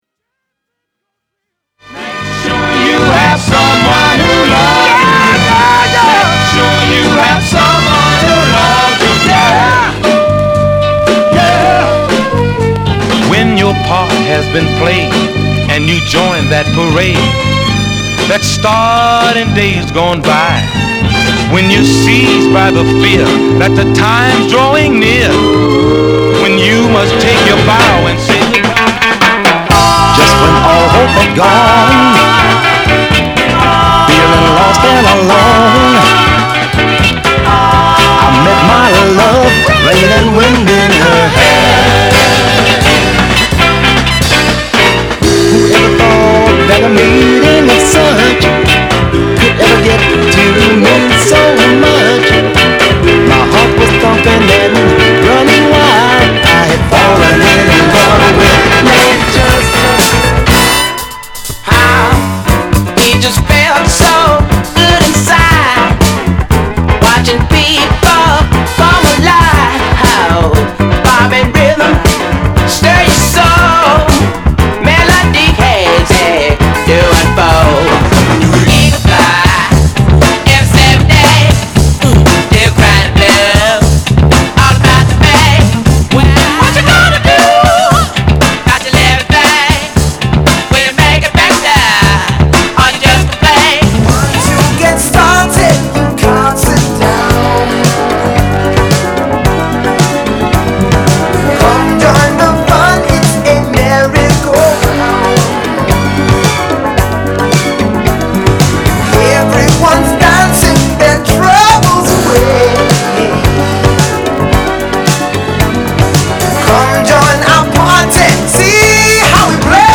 サイケデリックな浮遊感ソウル
/盤質/両面やや傷あり再生良好/US PRESS